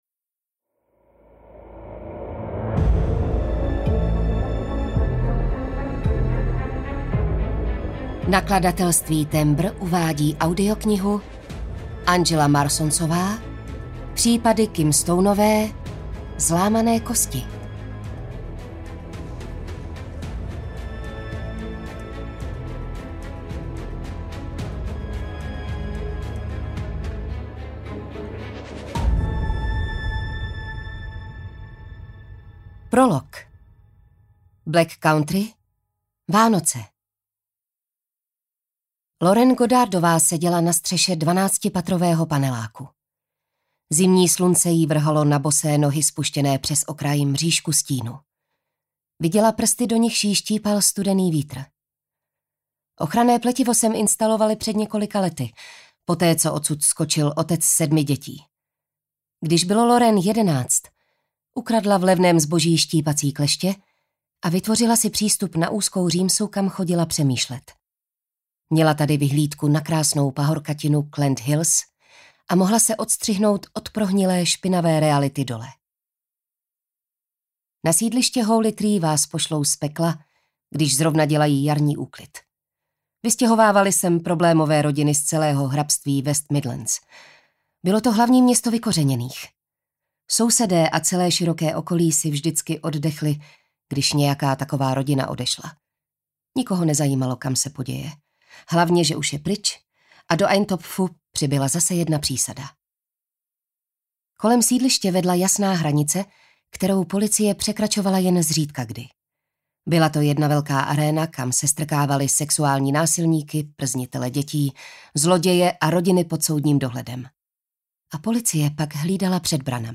Zlámané kosti audiokniha
Ukázka z knihy
Natočeno ve studiu Chevaliere